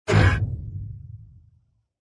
descargar sonido mp3 apunalar